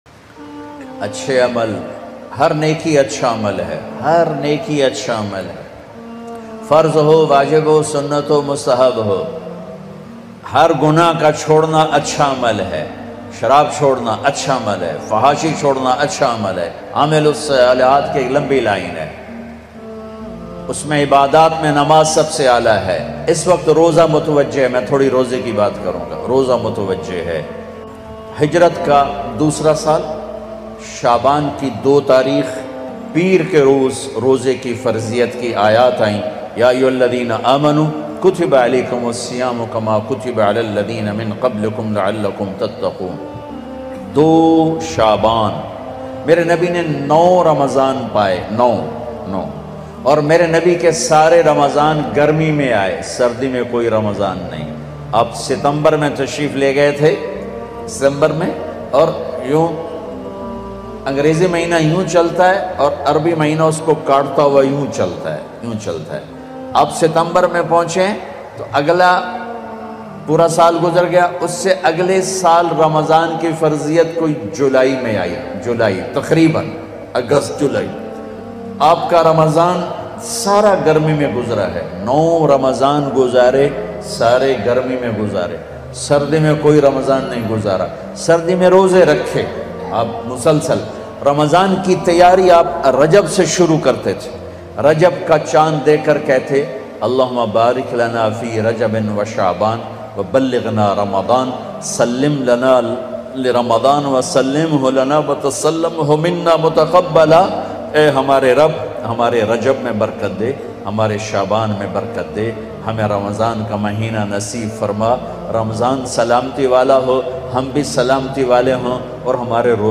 Ramzan Special Bayan Tariq Jameel Latest Bayan play online & download.
Ramzan Special Bayan mp3